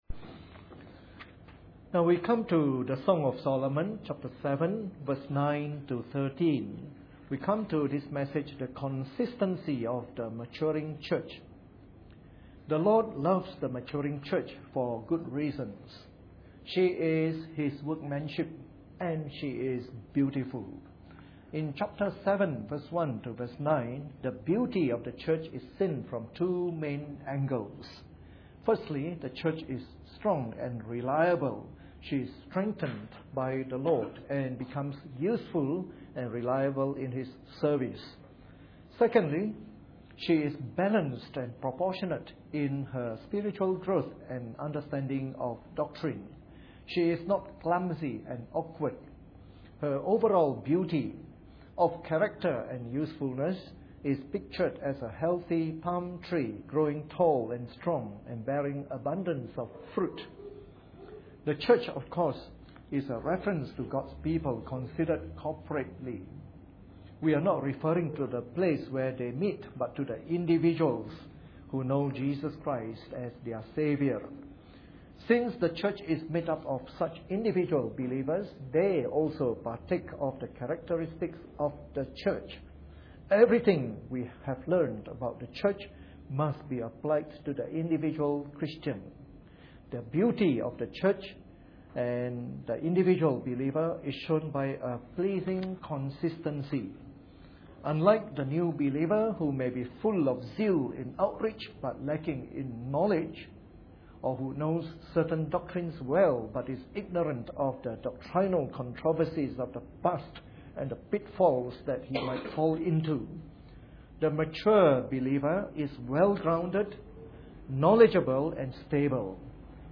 Preached on the 8th of February 2012 during the Bible Study from our series on the Song of Solomon.